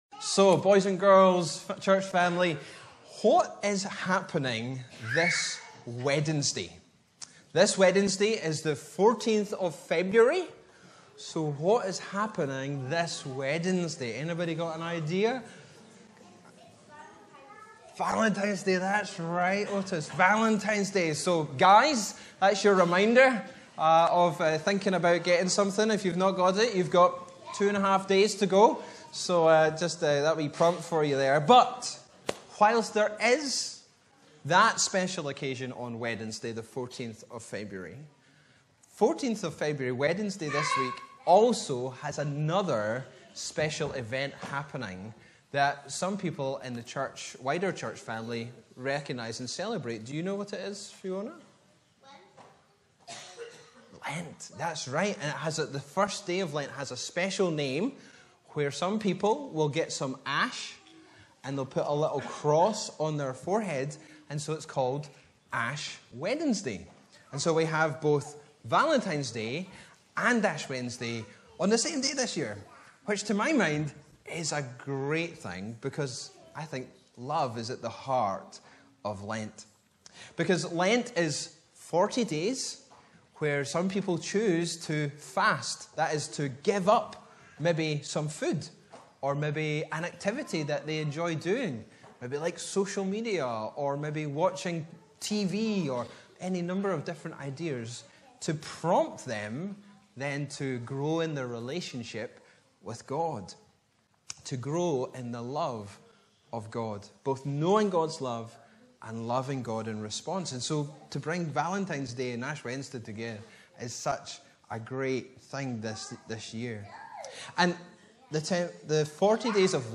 Upper Braes Joint Service
Bible references: Matthew 4:1-11 Location: Brightons Parish Church Show sermon text Sermons keypoints: - Hungry for God - Trusting God - Worship God alone